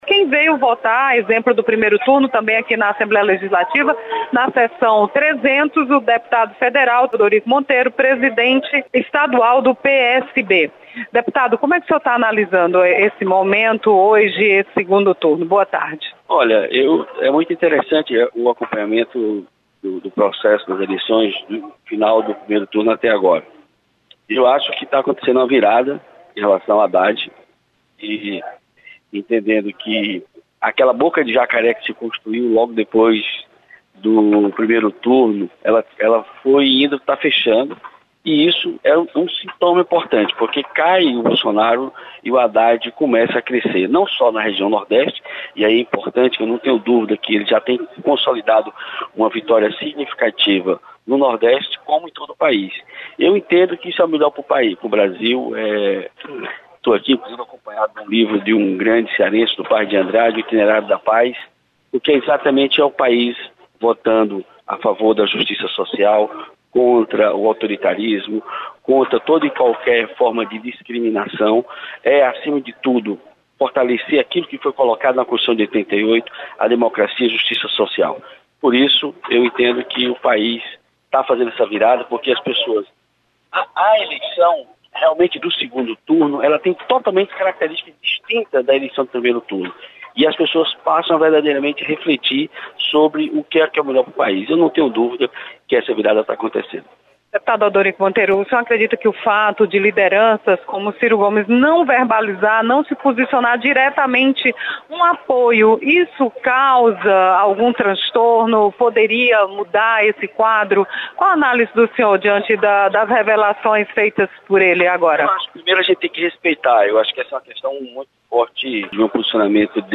Deputado Federal Odorico Monteiro vota na Assembleia Legislativa.